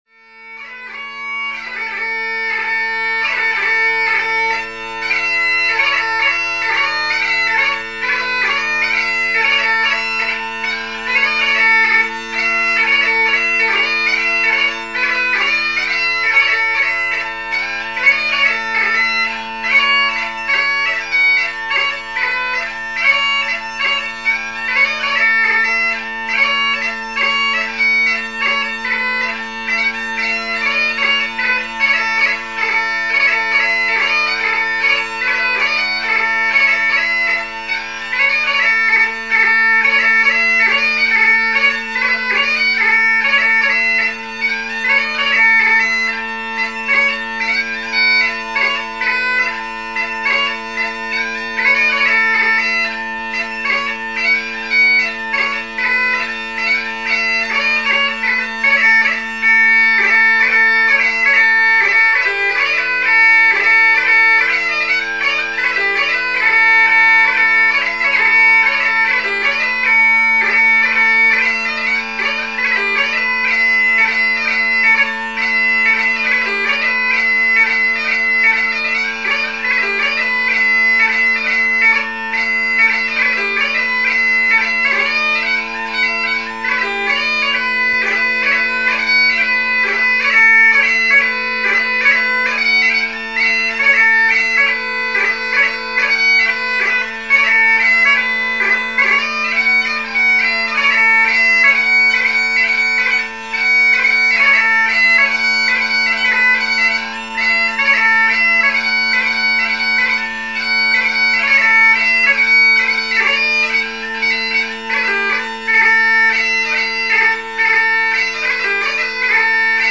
Readers might also like to check out this recording of strathspeys and reels from the PP Audio Archive where there are several examples of Reid’s light music performance.
06-Strathspeys-And-Reels-Played-On-Pipes_-Monymusk-Bogan-Lochan-Ca-The-Ewes-Piper-And-The-Dairymaid.mp3